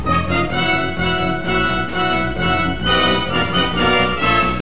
Dramatic Music Cue - 101k